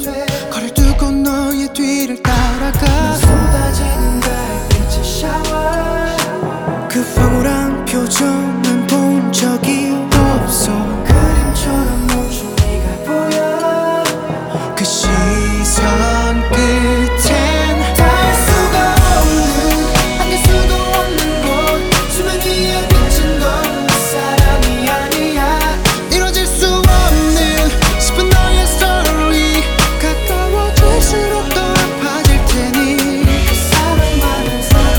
Жанр: Танцевальные / Поп / Рок / K-pop